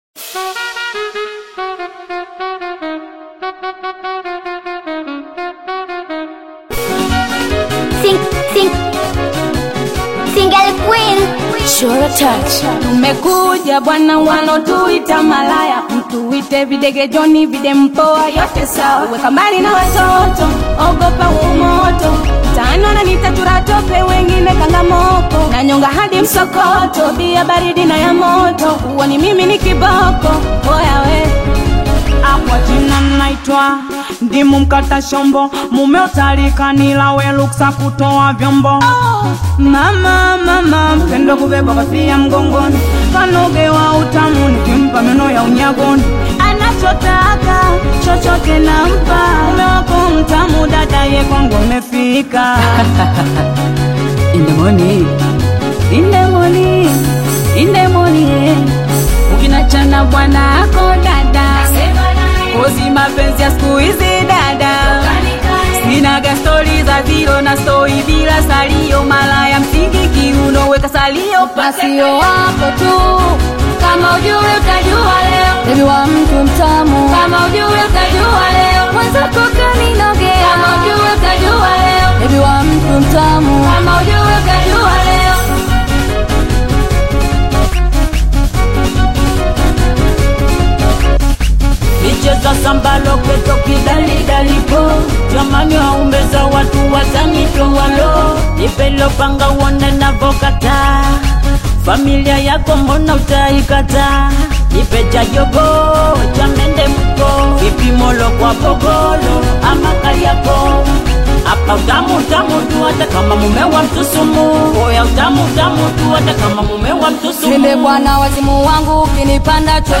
Tanzania’s Singeli scene just got hotter!